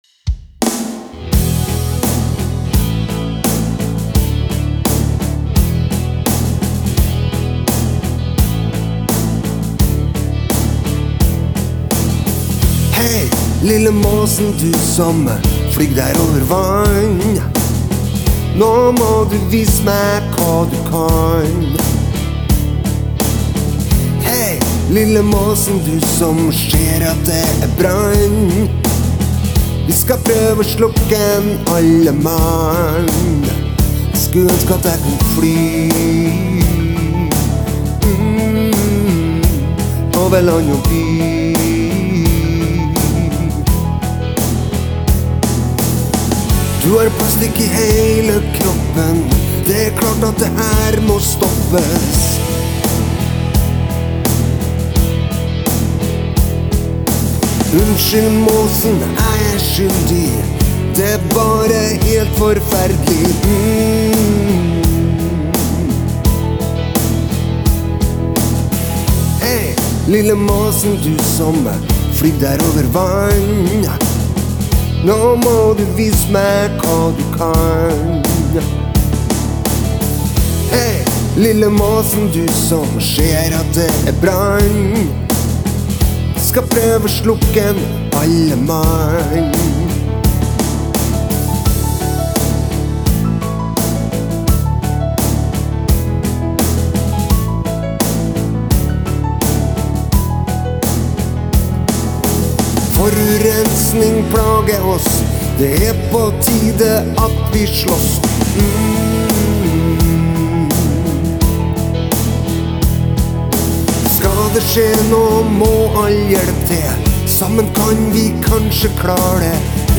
Kul skarptromme og fancy piano.
Hurra for plankebass og rock’n roll!